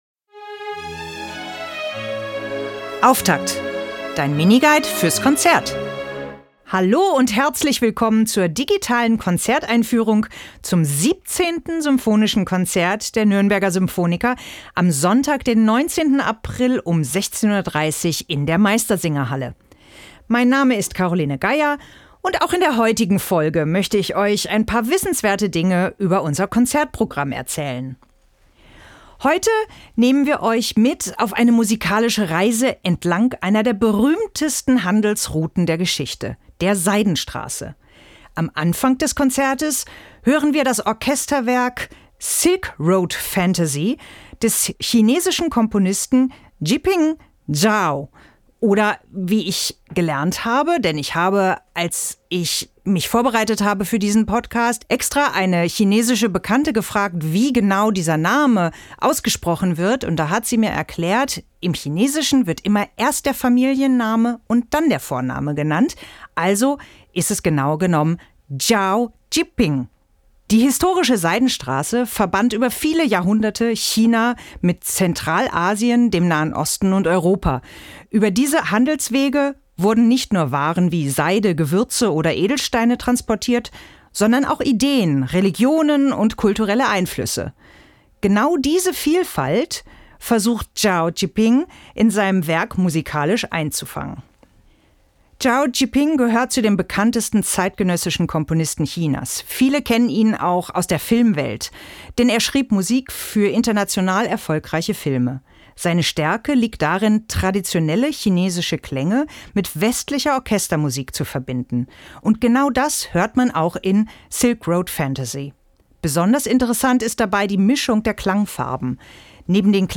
Podcast-Moderation